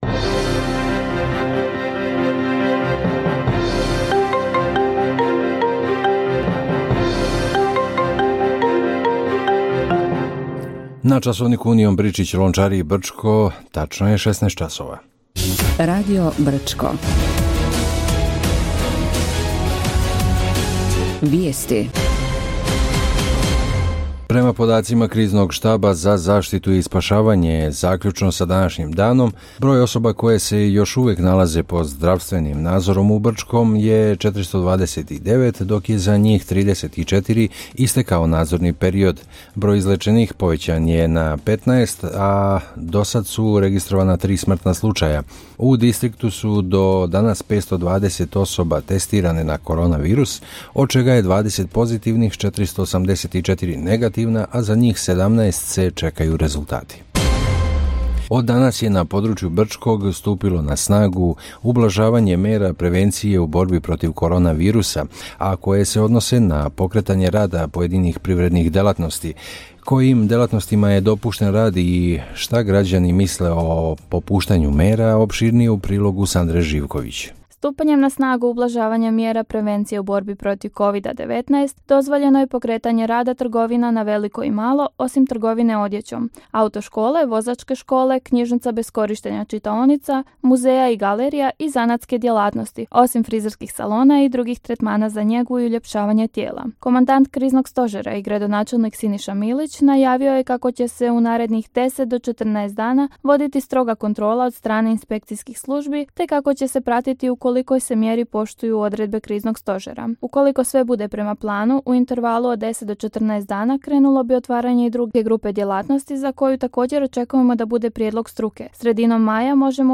Radio Brčko: Vijesti za ponedjeljak 04.05.2020. godine